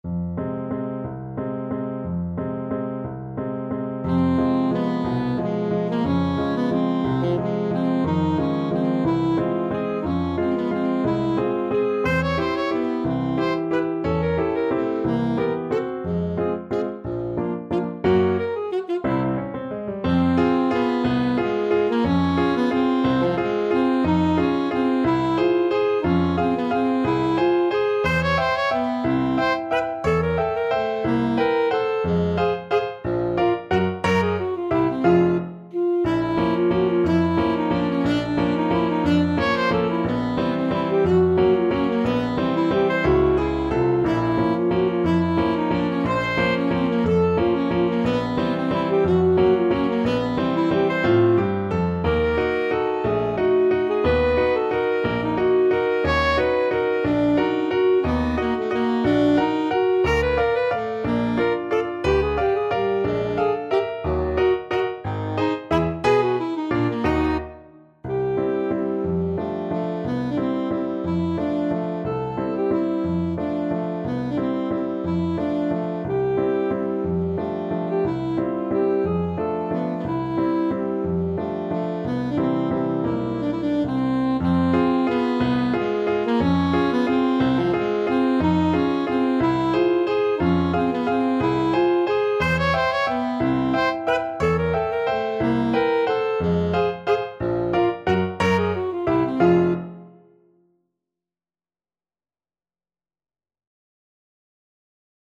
Alto Saxophone
Eb4-Db6
3/4 (View more 3/4 Music)
Allegro espressivo .=60 (View more music marked Allegro)
Classical (View more Classical Saxophone Music)